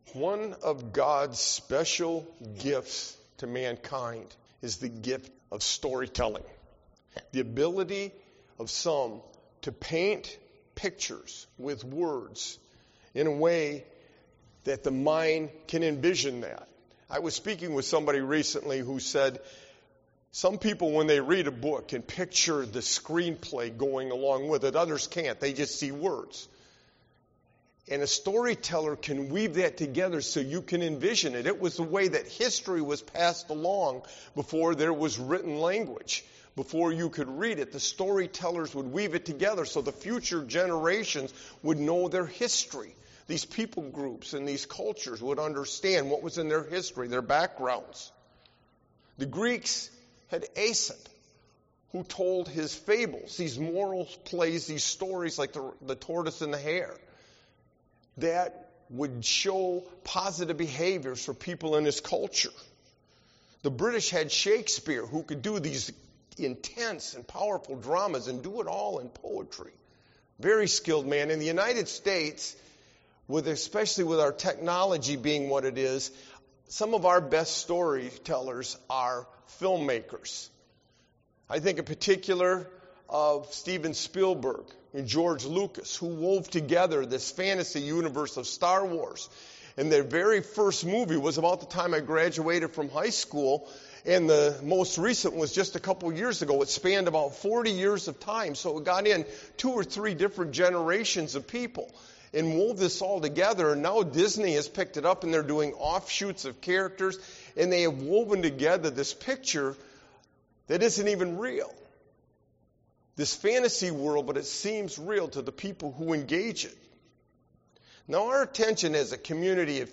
Sermon-Viewpoints-on-Death-and-Resurrection-of-Jesus-41722.mp3